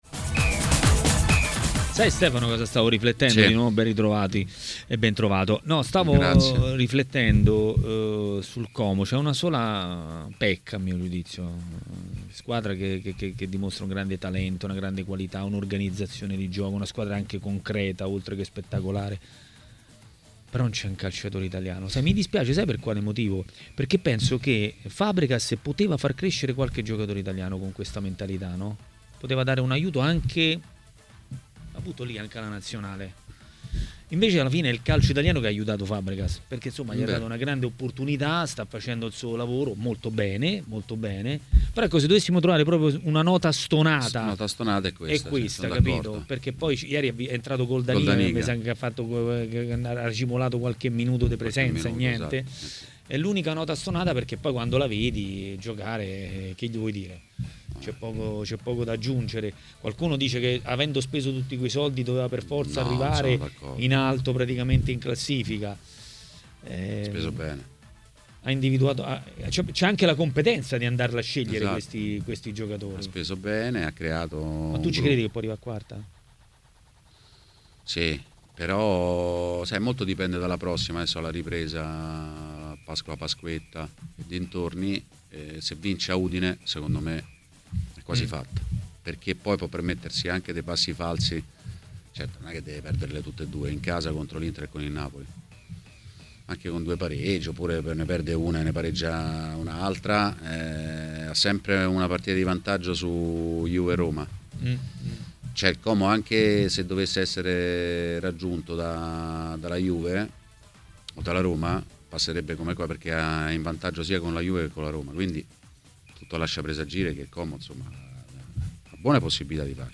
L'ex calciatore Antonio Paganin è stato ospite di TMW Radio, durante Maracanà.